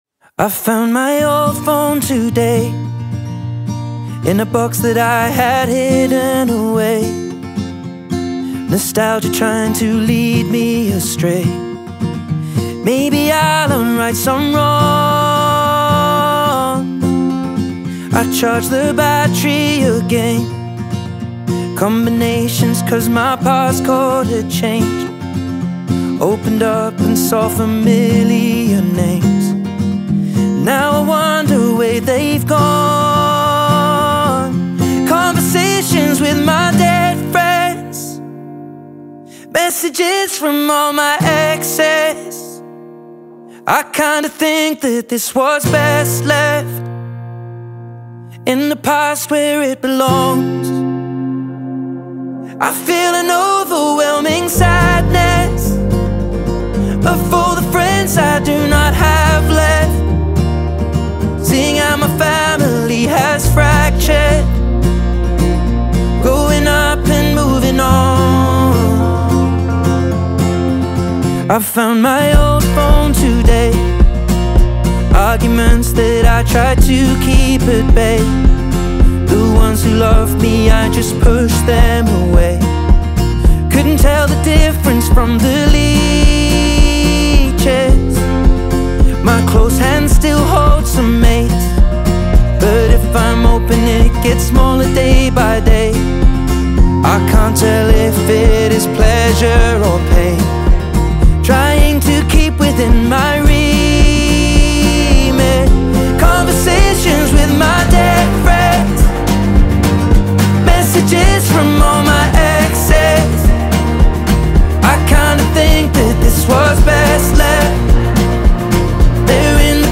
خیلی آهنگ احساساتی هست